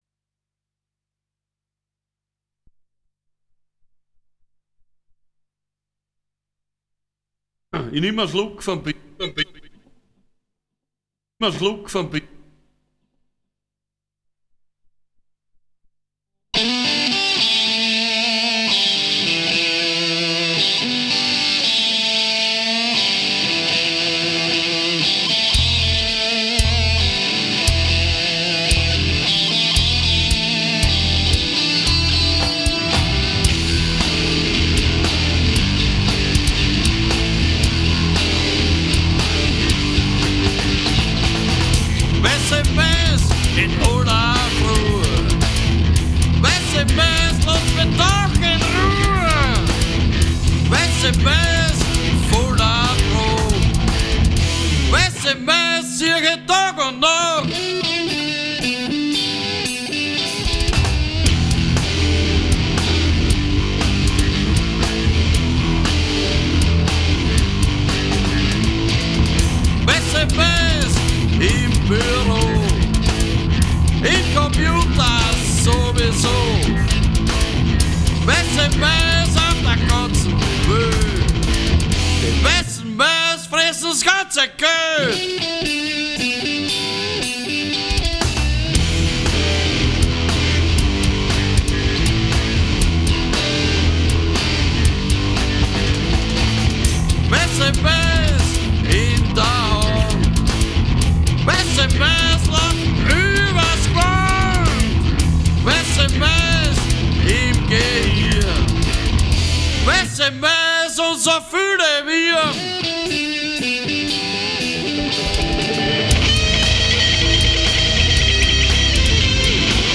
Entwicklung hin zu härterer Musik geprägt